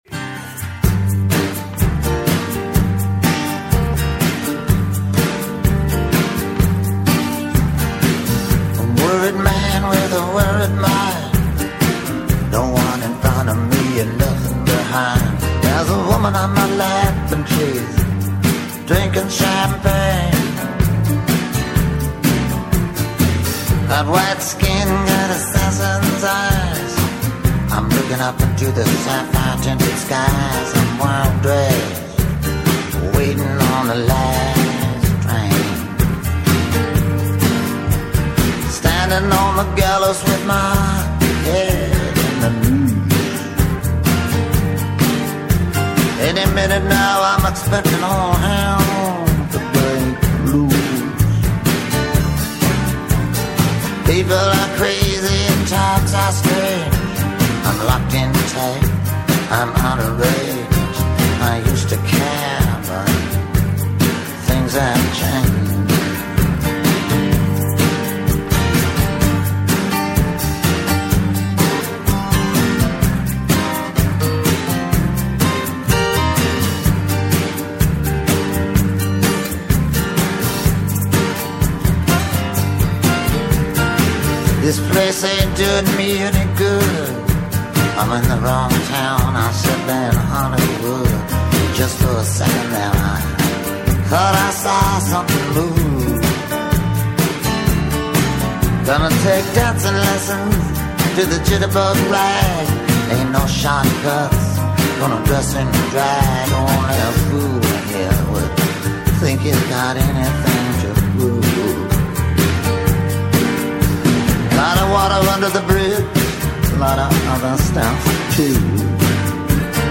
Έκτακτη ενημερωτική εκπομπή